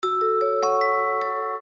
мелодичные
без слов
ксилофон
Уведомление на ксилофоне